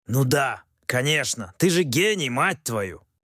• Озвучка текста на русском и других языках.